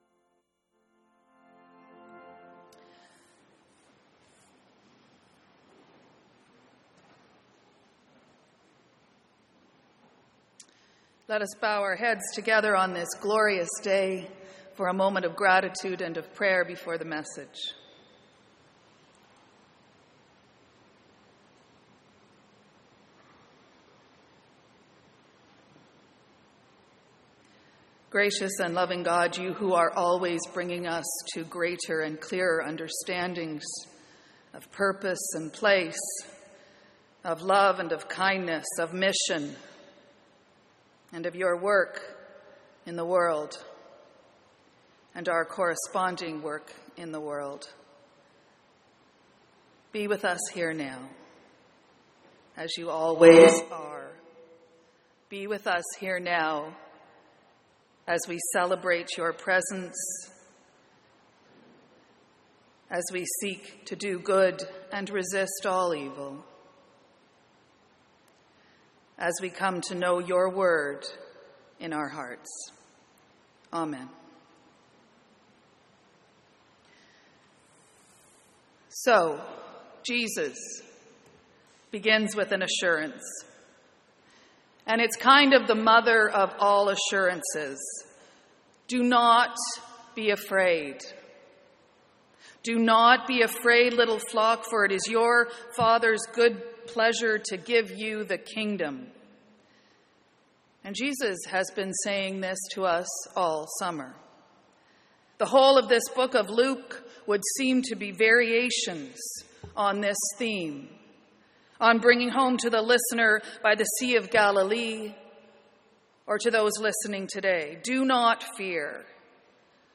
Sermons & Livestreams | Metropolitan United Church